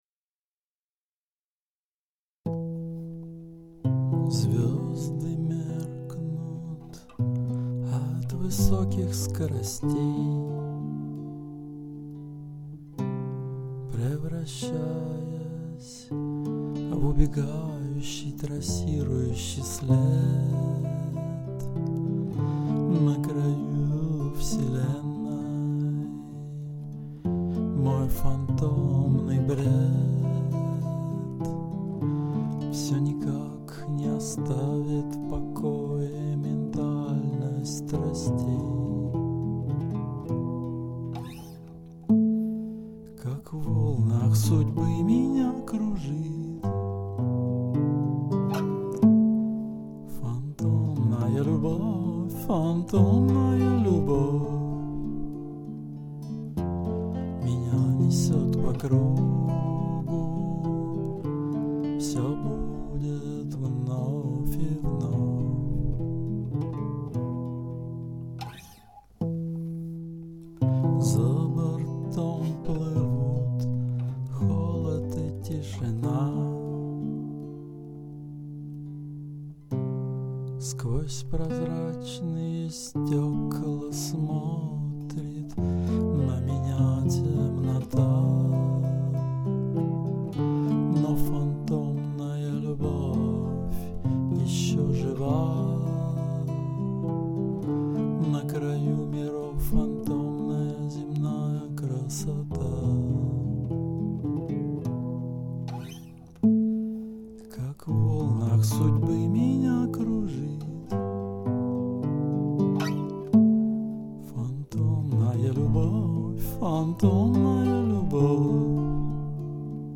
• Жанр: Кантри